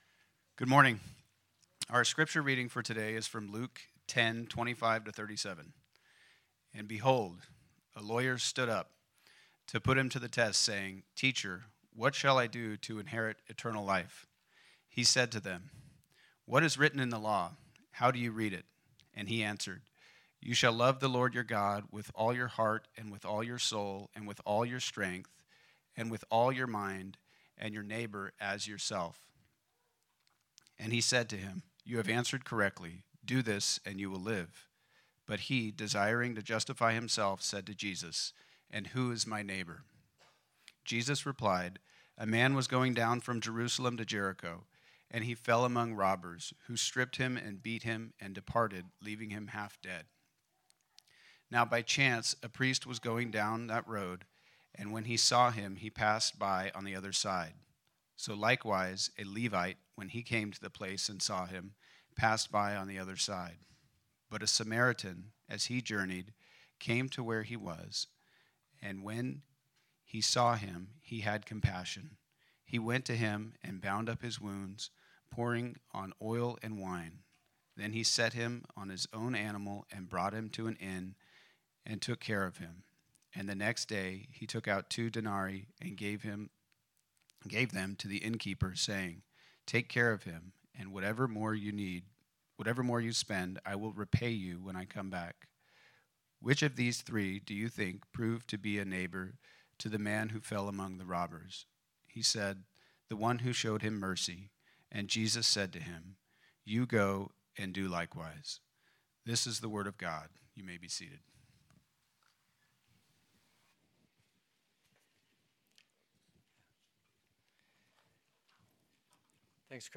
Type: Sermon